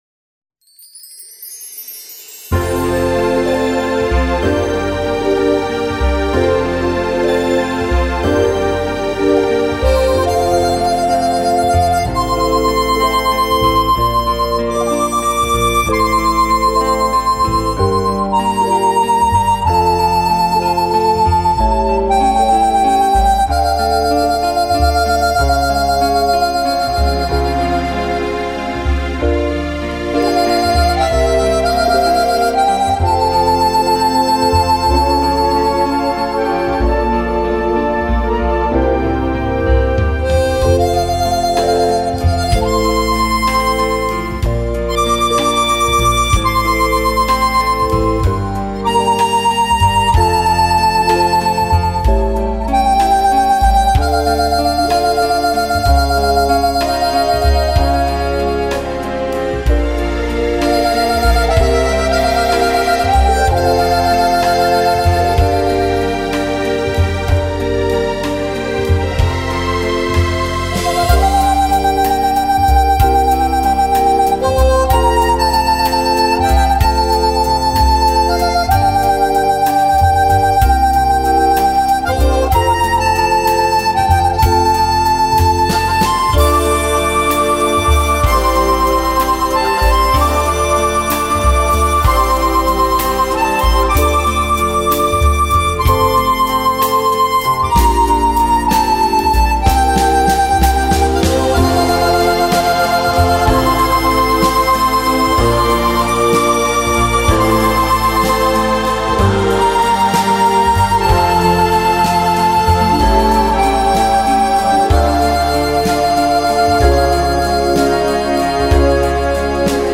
آهنگ بی کلامموسیقی
دانلود آهنگ سازدهنی- هارمونیکا